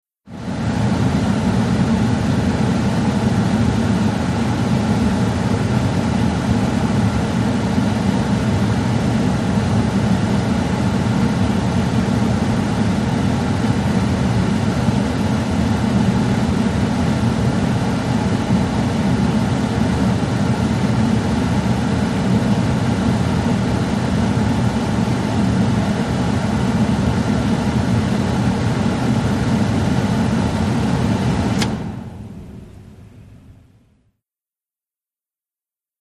VEHICLES 1973 DODGE POLARA V8: INT: Air conditioner, run, switch off.